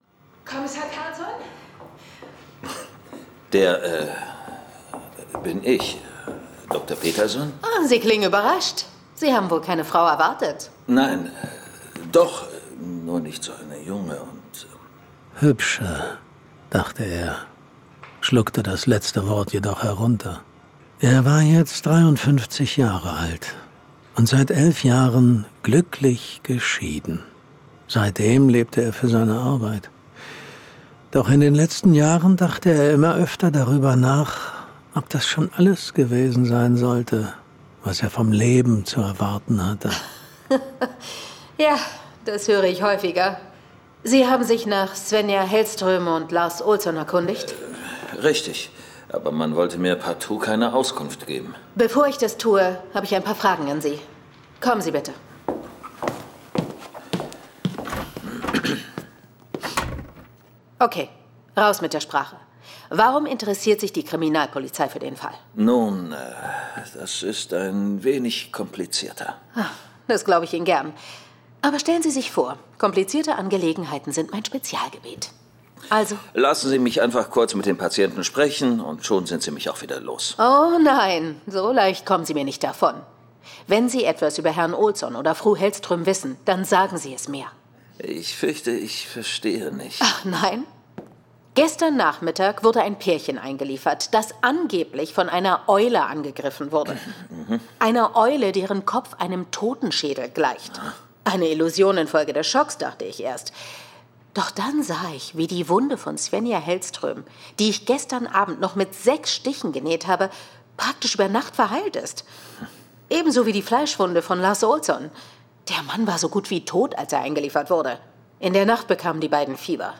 John Sinclair - Folge 184 Die Bluteulen. Hörspiel.